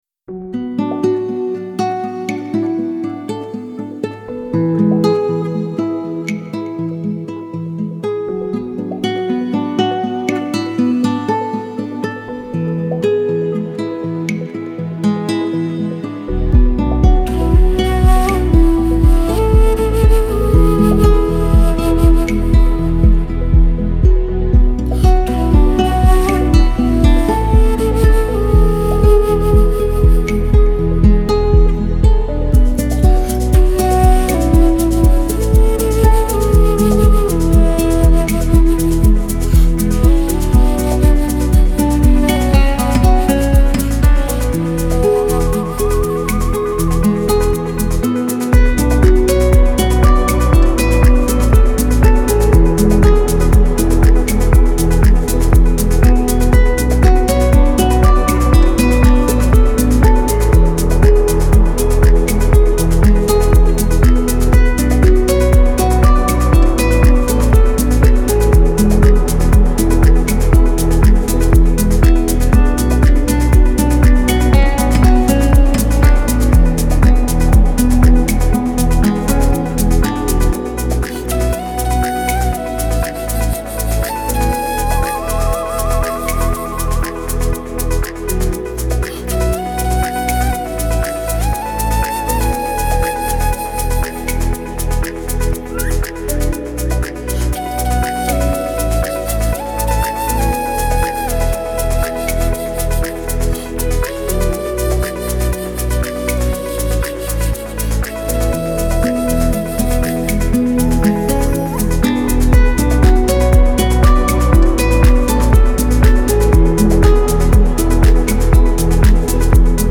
موسیقی بی کلام تلفیقی موسیقی بی کلام ریتمیک آرام